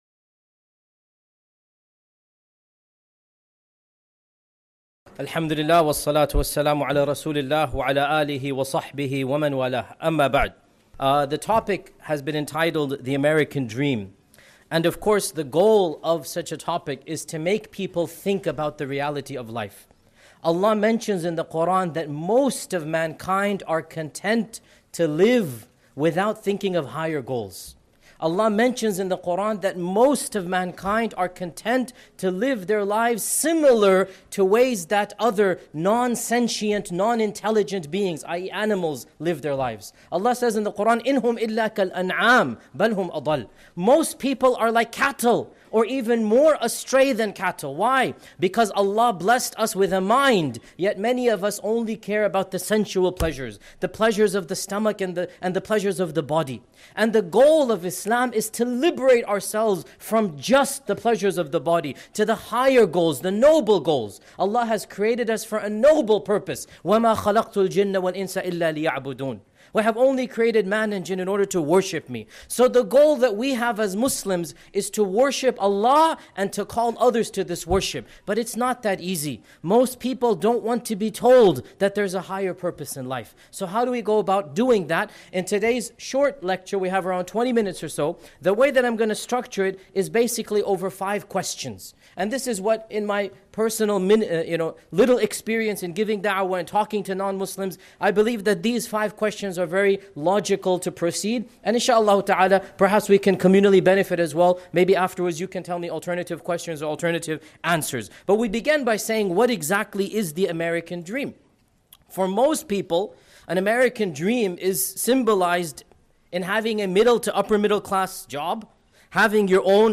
In a landmark talk delivered in Detroit in May 2014, Shaykh Dr. Yasir Qadhi — scholar, professor, and one of the most influential Muslim voices in the English-speaking world — takes this univer
Drawing on Quranic wisdom, rational philosophy, and the lived reality of modern Western society, he walks his audience through five essential questions that every sincere seeker of truth must confront.